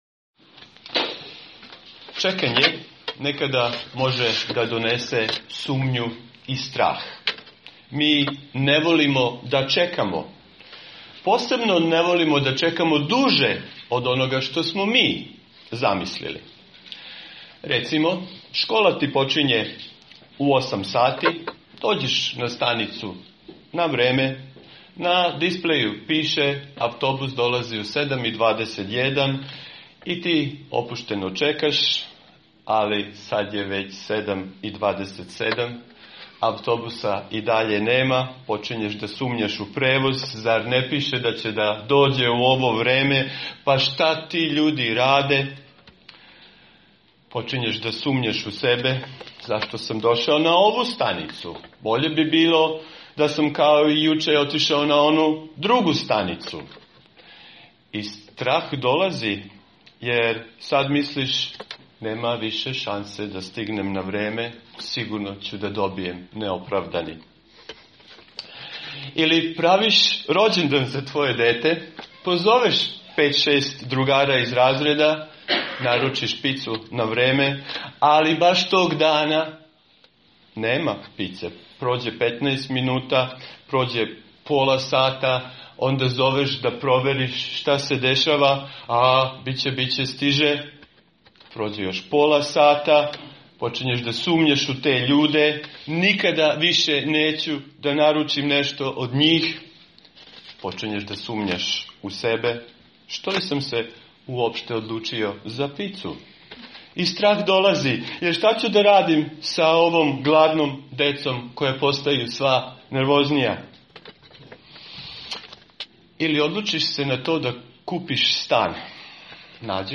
Propoved: Vera koja se bori sa sumnjom - 1. Mojsijeva 15:1-6
Serija: Avram: otac svih koji veruju | Poslušajte propoved sa našeg bogosluženja.